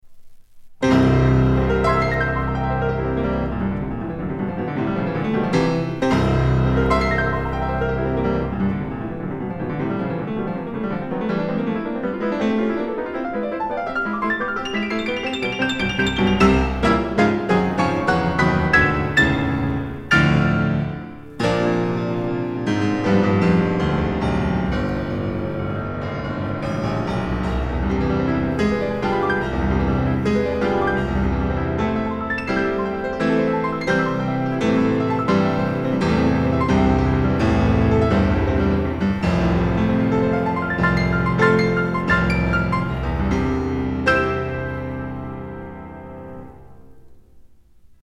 CB3を使うと、ほんの少し雑音が加わるように感じられます。
比較試聴した時の楽曲は全てDSD 5.6M で録音しました。
クラシック、ピアノ独奏